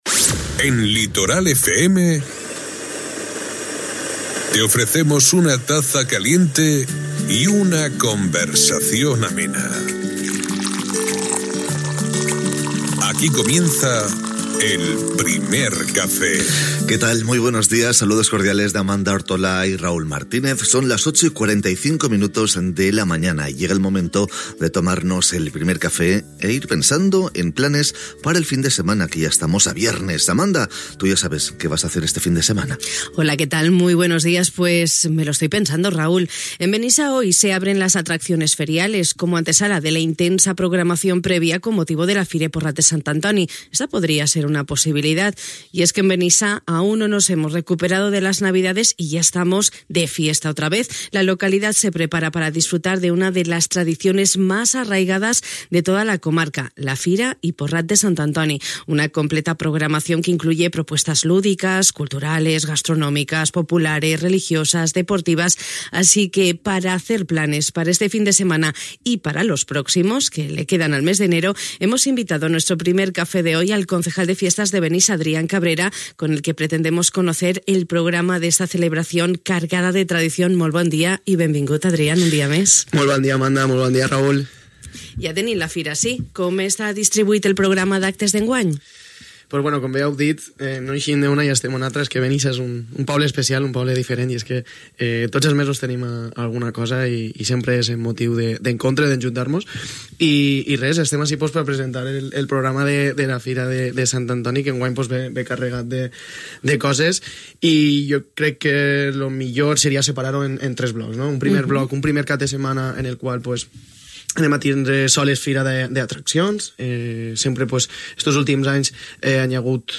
Una completa programació que inclou propostes lúdiques, culturals, gastronòmiques, populars, religioses, esportives… que ocupessin els propers caps de setmana de gener i que hem pogut conèixer al Primer Cafè de hui amb el regidor de Festes de Benissa, Adrián Cabrera.